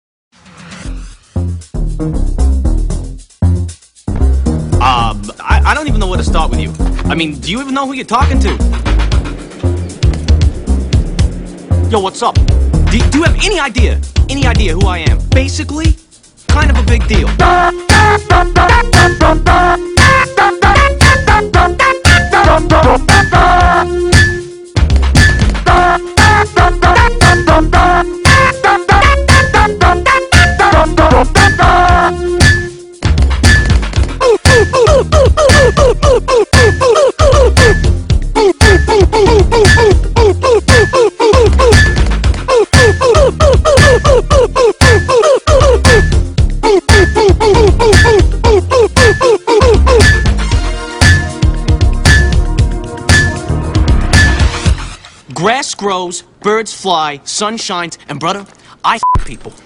Šis akronimas iššifruojamas taip: YouTube Poop Music Video. Tai tiesiog iš skirtingų video gabalėlių sulipdytas klipas su prie jo priderinta muzika.
Gerai parinkta muzika ir puikiai priderinti garsai, būtų galima naudoti net kaip telefono skambėjimo melodiją.